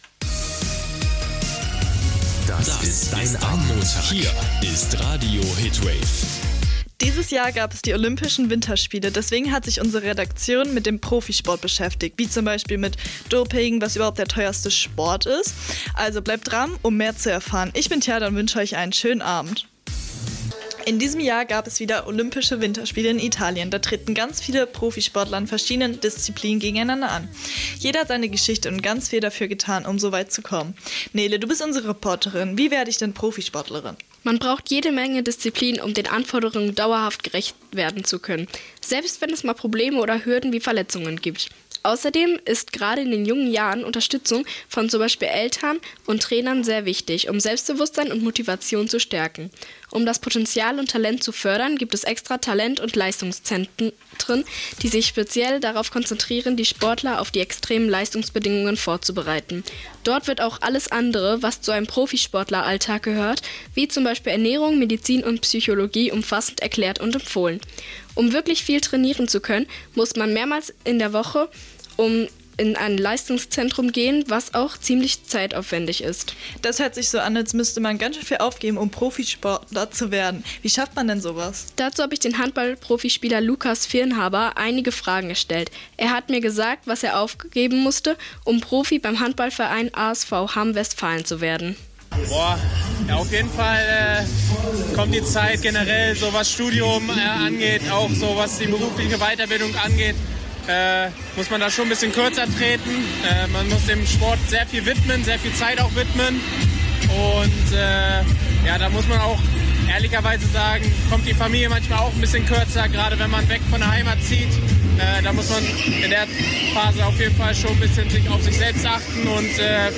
Sendung der Hitwave-Jugendredaktion zum Thema Profisport